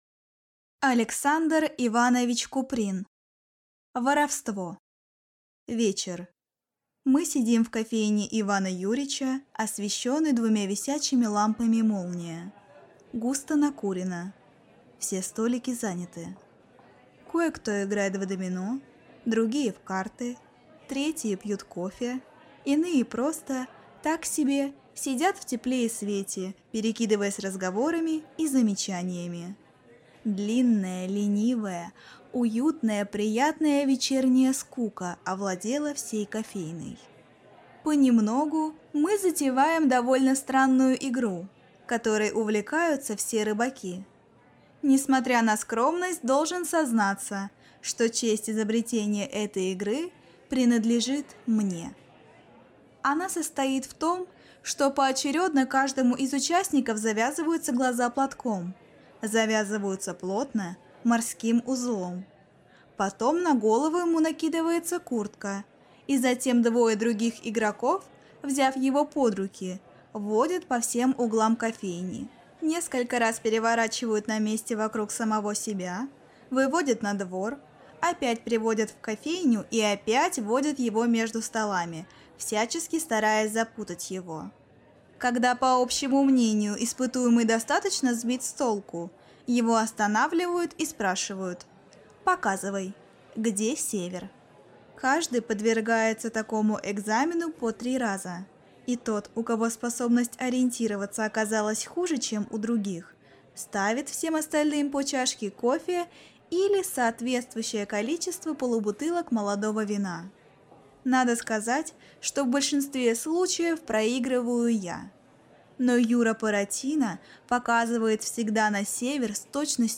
Aудиокнига Воровство